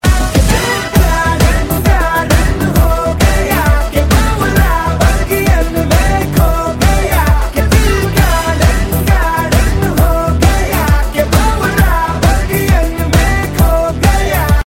File Type : Bollywood ringtones